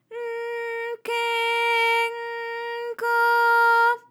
ALYS-DB-001-JPN - First Japanese UTAU vocal library of ALYS.
k_N_ke_N_ko.wav